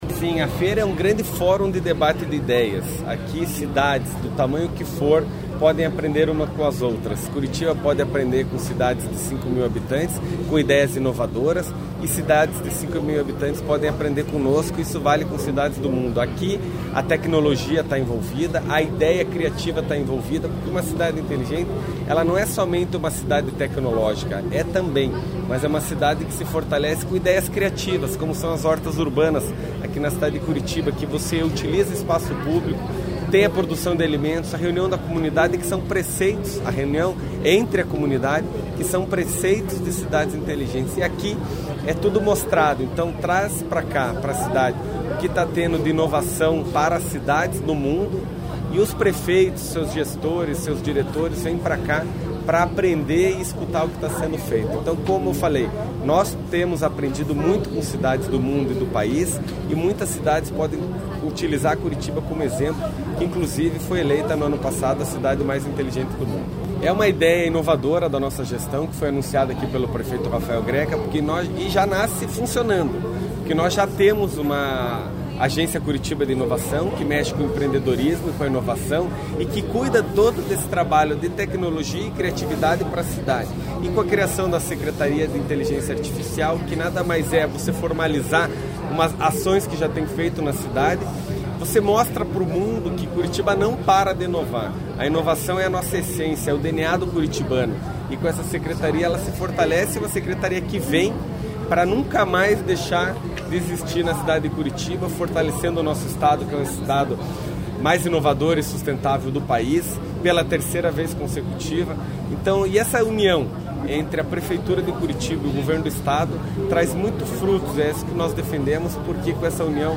Sonora do secretário Estadual das Cidades, Eduardo Pimentel, sobre a abertura do Smart City Expo Curiitba 2024